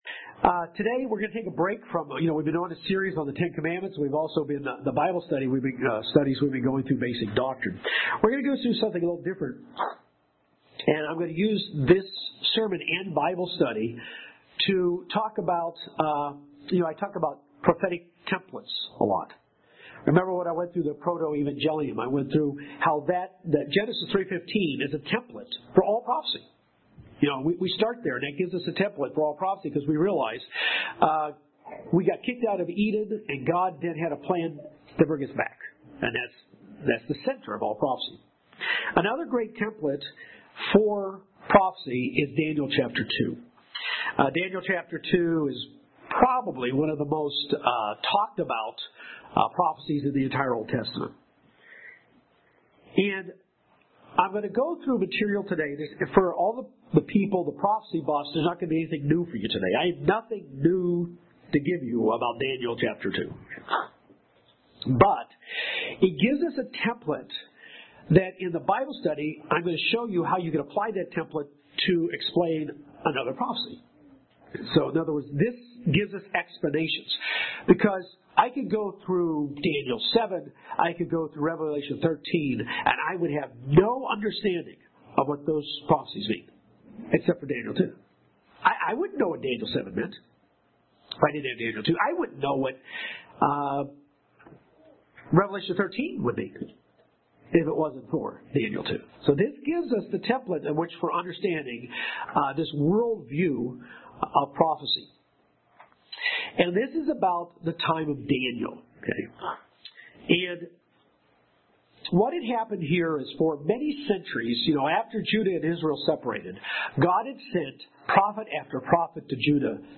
Daniel 2 is a remarkable template for prophecy. In this sermon we will examine exactly what that means.